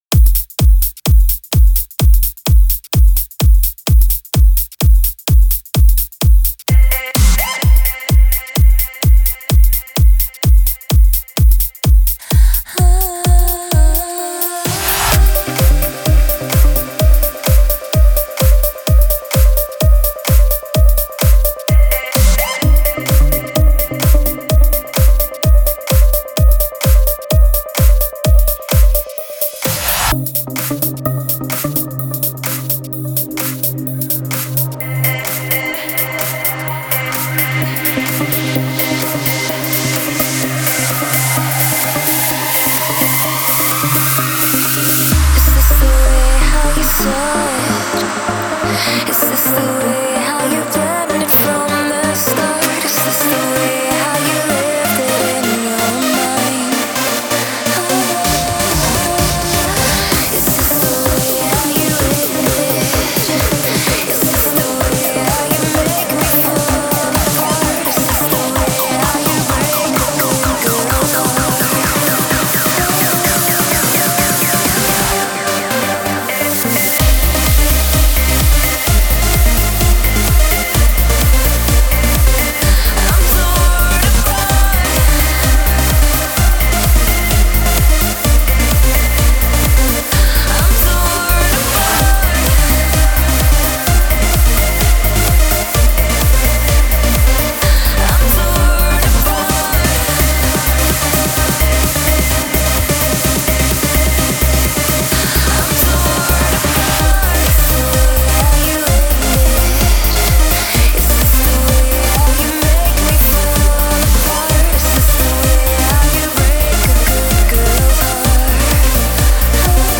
Категория: Электро музыка » Транс